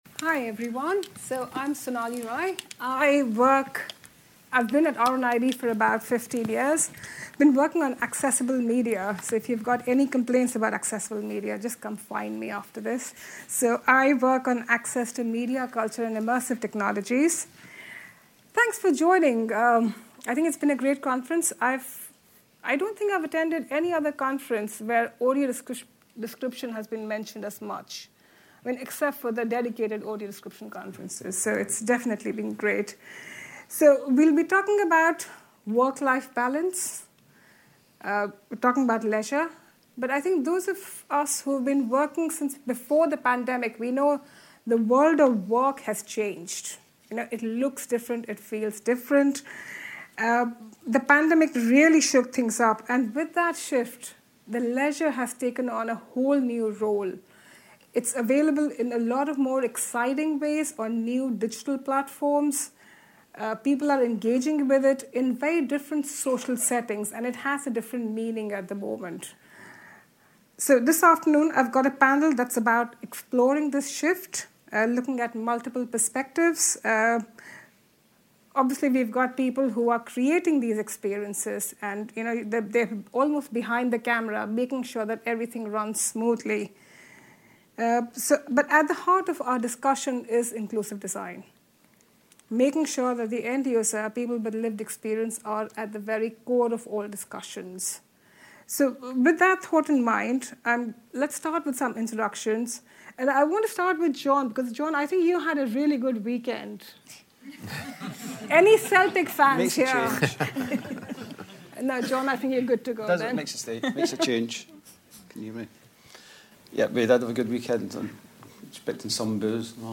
Leisure & Entertainment session took place on day two of RNIB Scotland's Inclusive Design for Sustainability Conference.
Our panel will explore this shift from the perspective of the provider, the consumer, and the community, with a strong emphasis on inclusive design. A panel discussion